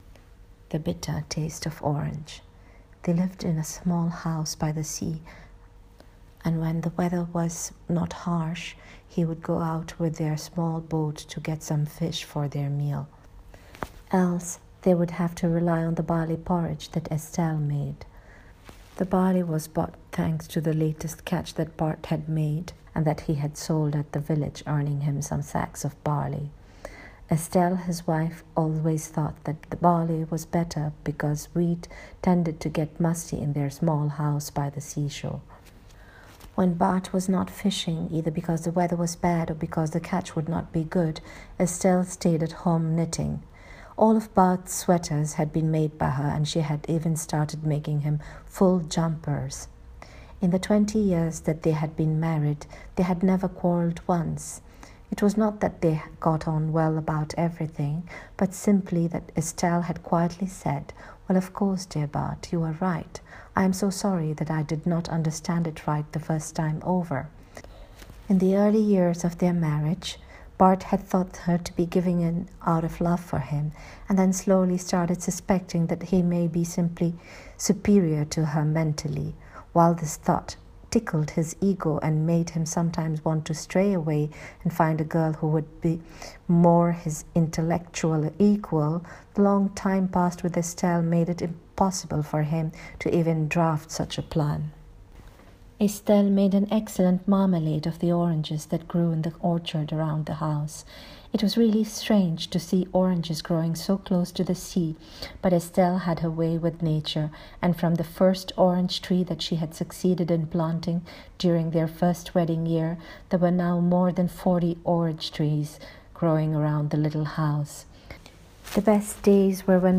Reading of the story: